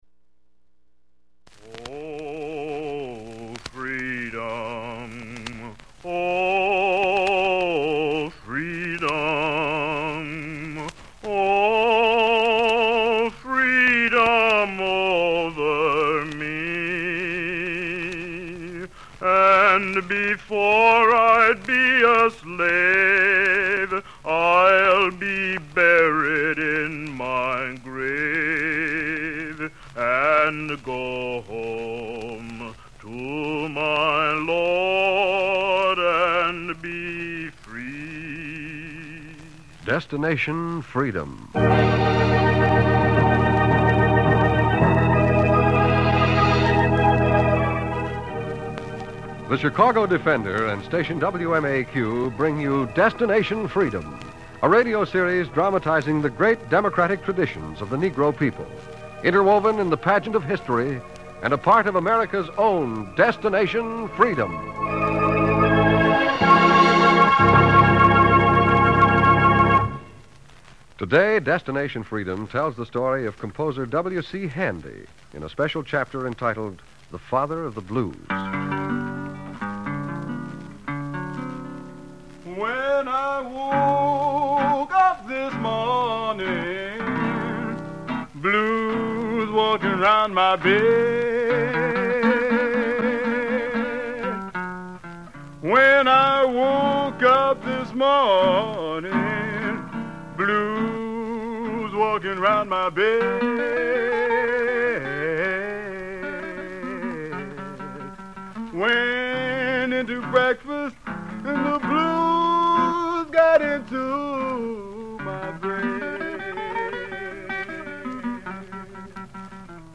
The series was dedicated to dramatizing the lives and achievements of notable African Americans, bringing their stories into the homes of listeners during a time when such representations were scarce. "The Father of the Blues" - Air Date: September 12, 1948 The twelfth episode, "The Father of the Blues," aired on September 12, 1948, and centers around the life and legacy of W.C. Handy,